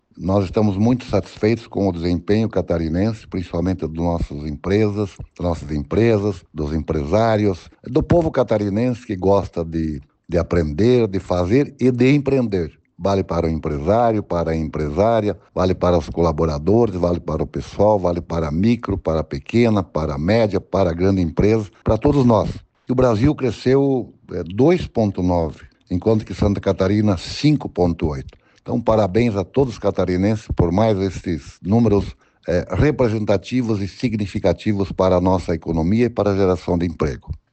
O secretário de Estado da Indústria, Comércio e Serviço, Silvio Dreveck, fala sobre os resultados alcançados por Santa Catarina: